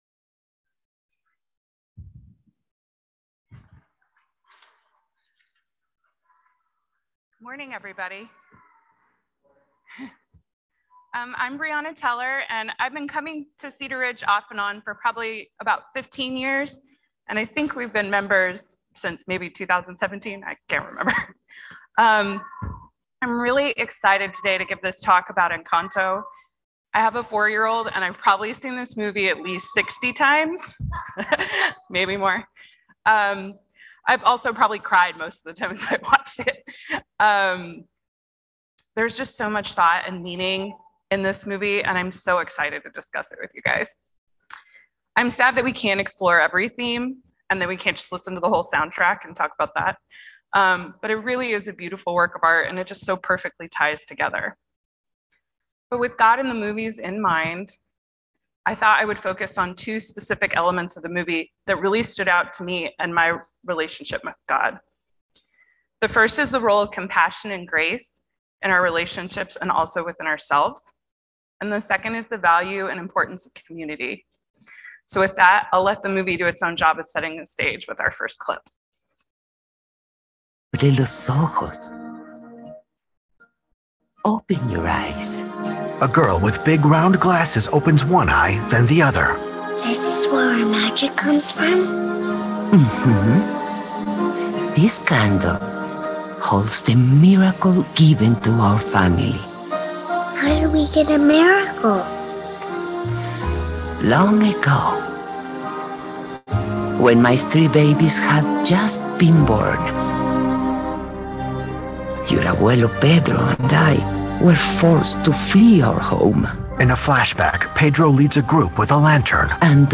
Speakers will use clips from a movie to explore underlying themes, and reflect on how this moves them to respond as followers of Jesus.